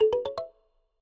ring.ogg